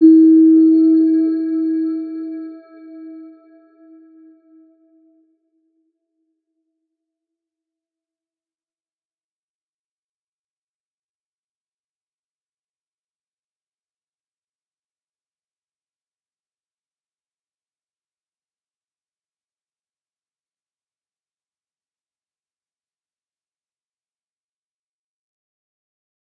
Round-Bell-E4-f.wav